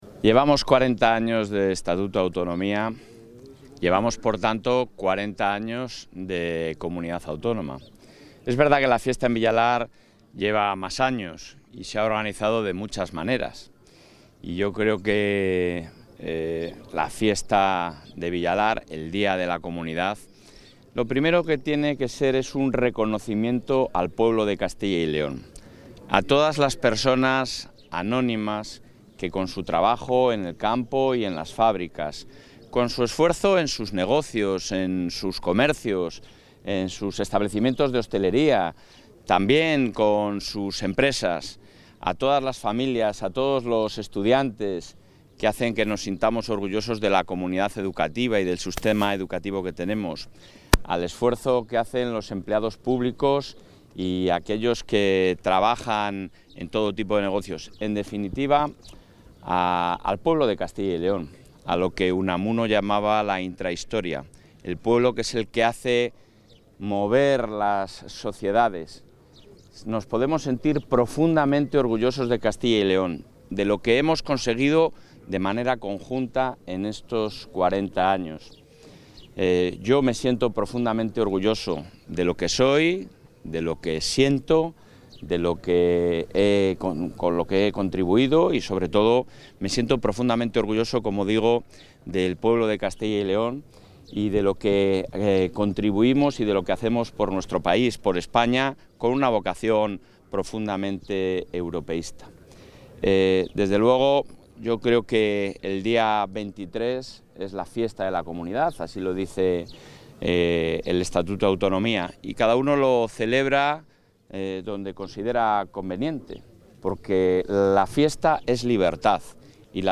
El presidente Mañueco ha asistido hoy a Villalar de los Comuneros para celebrar el Día de la Comunidad.
Intervención del presidente de la Junta.